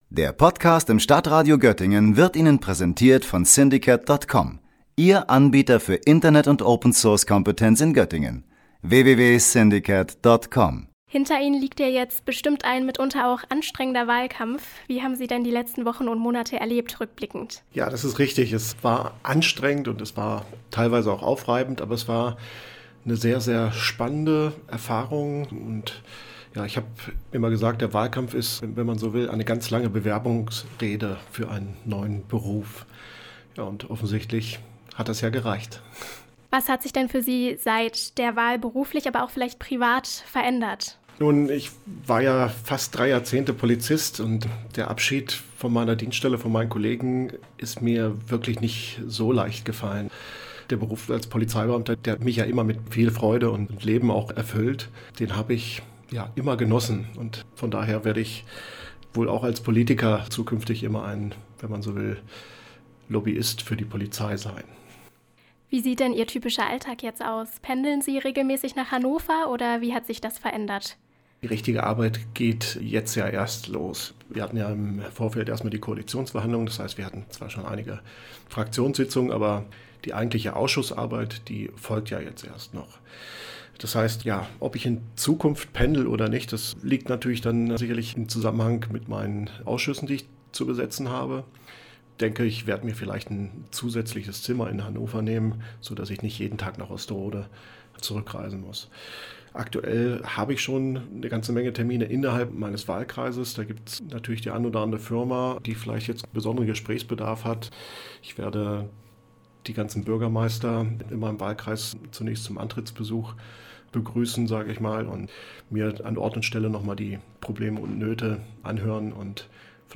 Beiträge > Er möchte durch persönlichen Kontakt überzeugen – SPD-Landtagsabgeordneter Alexander Saade im Interview - StadtRadio Göttingen